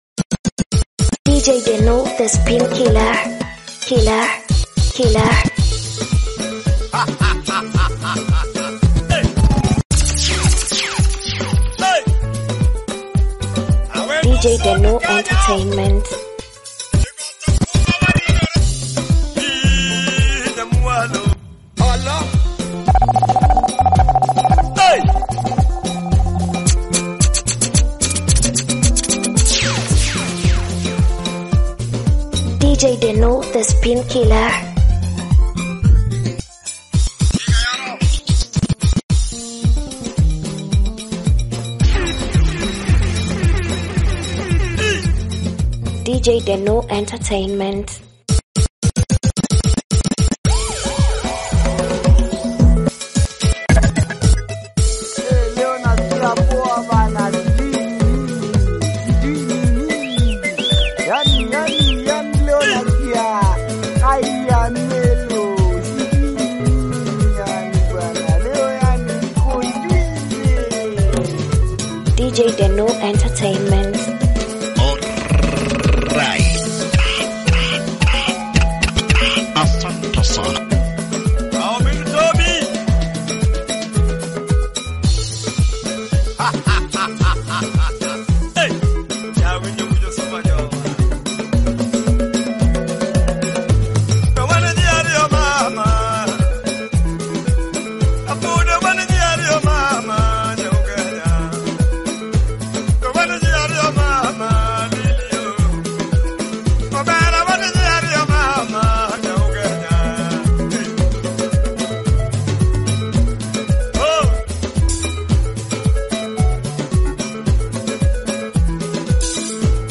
Luo music mixes